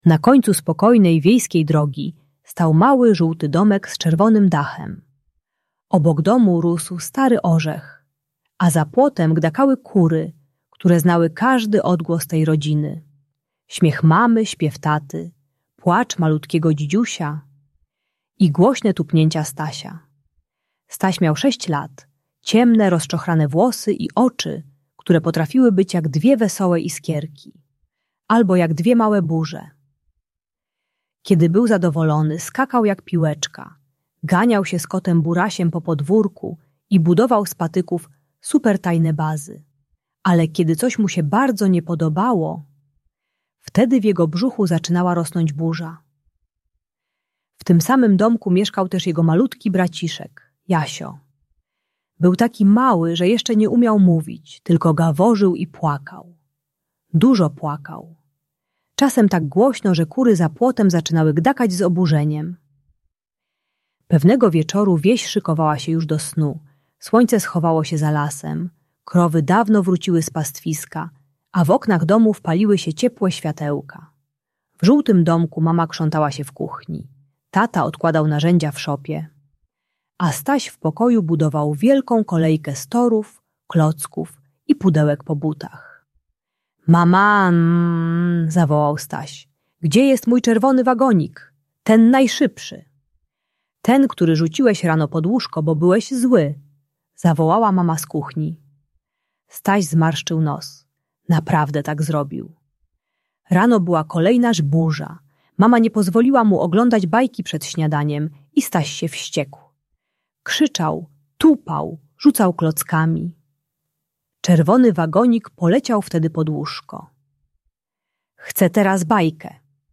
Historia Stasia uczy techniki "Burzowych Przełączników" - jak zamienić tupanie, krzyczenie i trzaskanie drzwiami na bezpieczne sposoby wyrażania złości. Idealna audiobajka o radzeniu sobie ze złością dla młodszych dzieci szkolnych i starszych przedszkolaków.